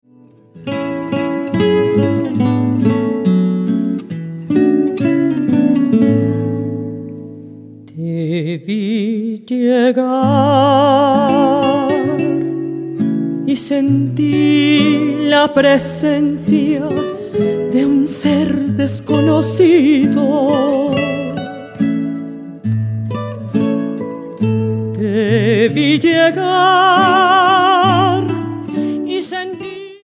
mezzosoprano
guitarras